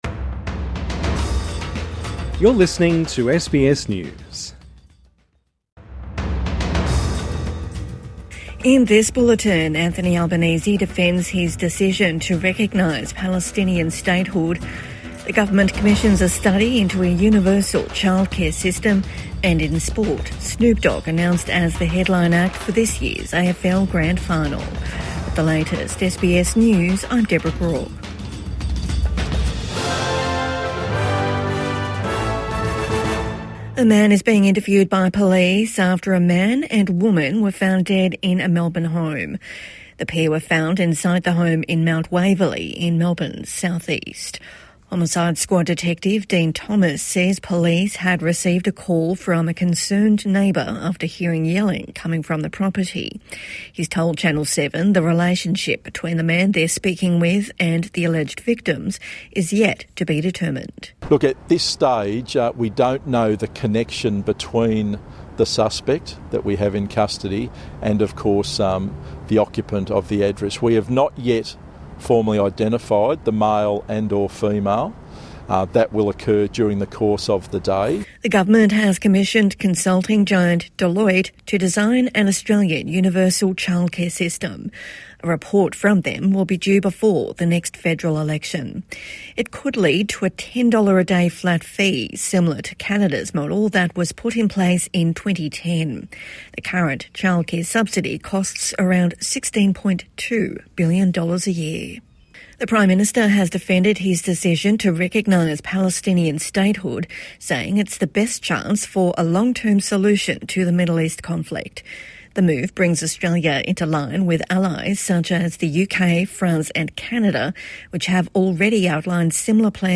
Government commissions study into universal childcare | Midday News Bulletin 12 August 2025